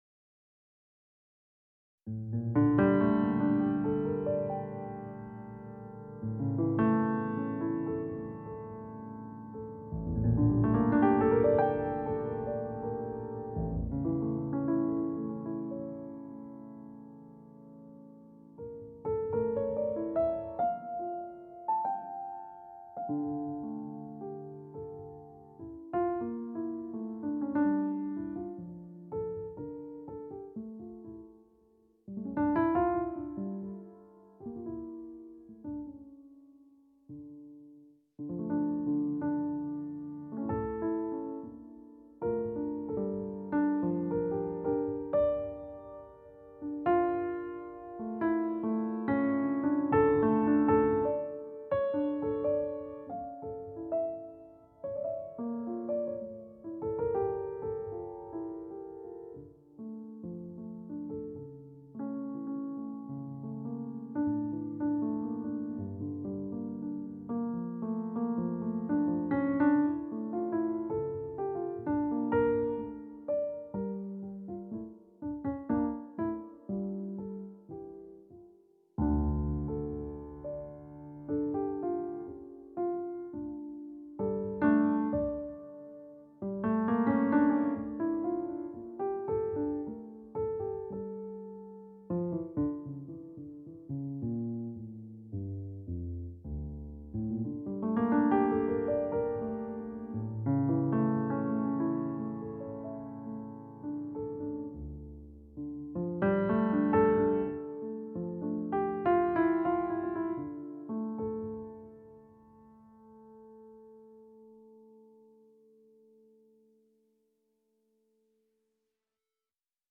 a kind of sonatina